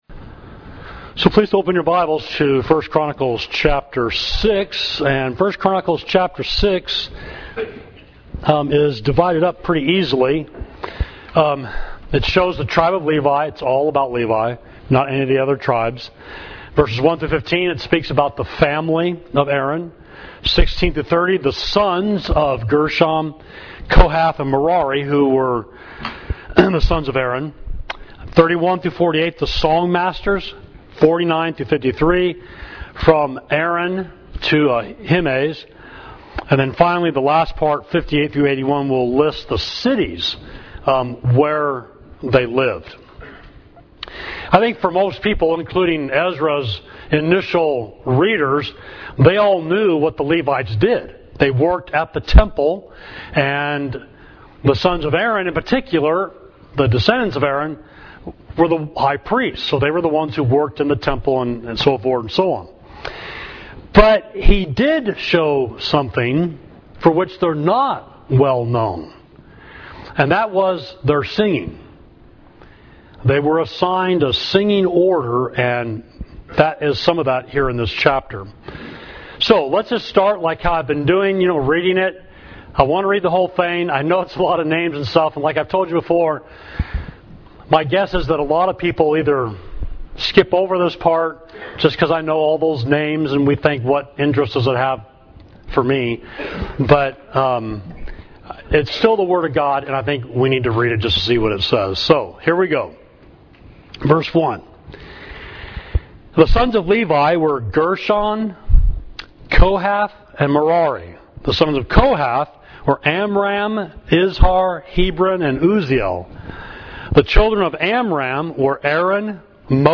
Sermon: Christians Are Spiritual Levites